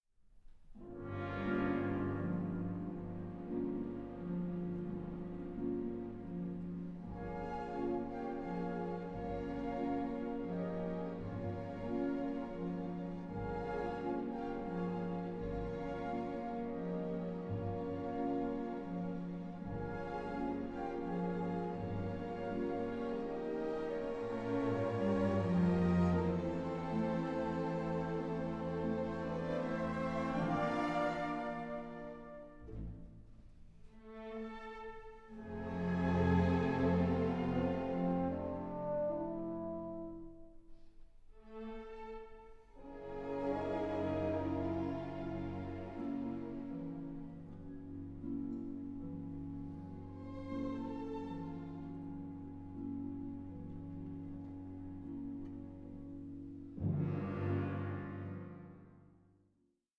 Allegro 6:09